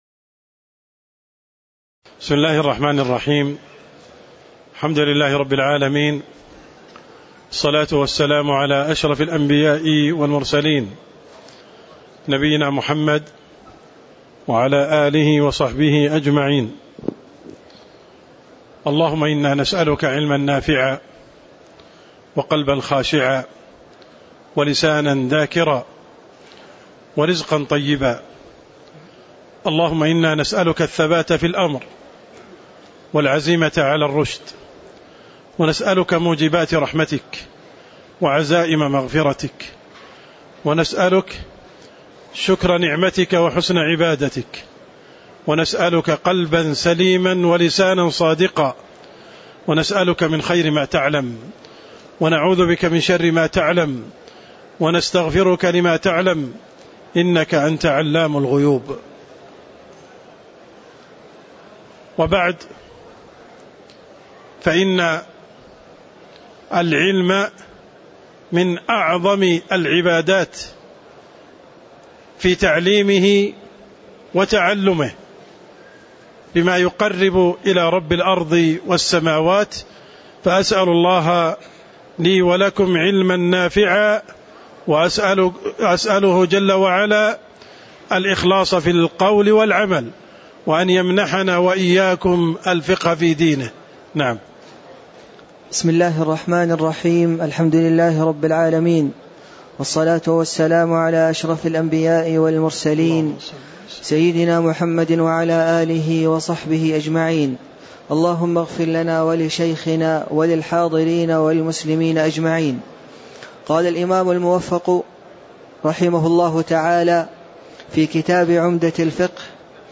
تاريخ النشر ٢٢ صفر ١٤٣٨ هـ المكان: المسجد النبوي الشيخ